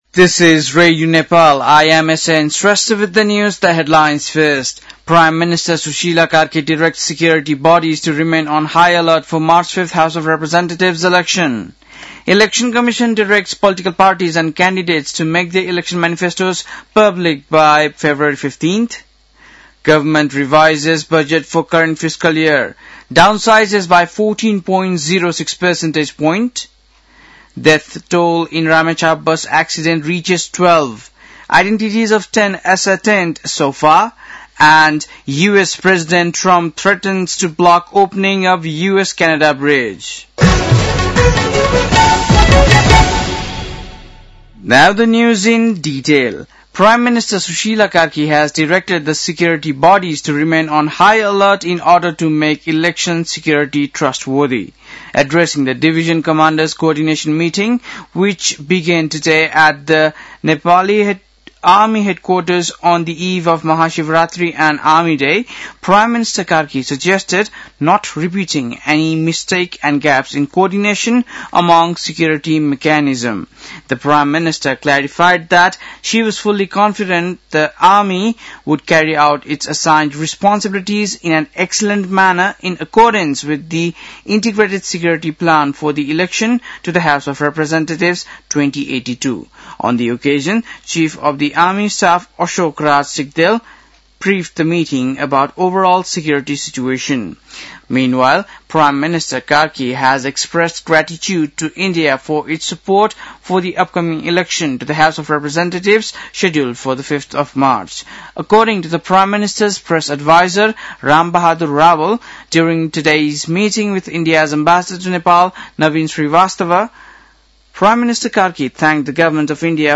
बेलुकी ८ बजेको अङ्ग्रेजी समाचार : २७ माघ , २०८२
8-pm-english-news-10-27.mp3